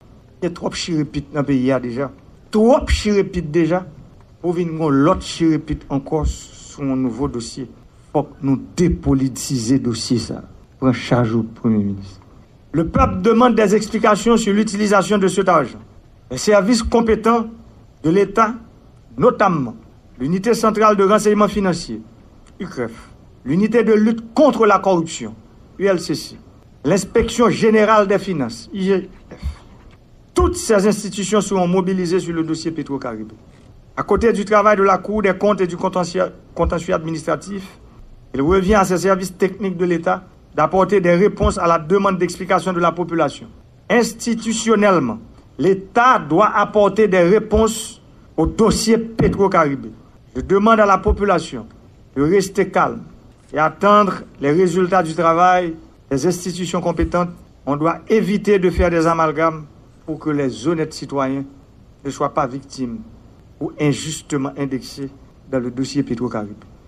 Deklarasyon Prezidan Jovenel Moise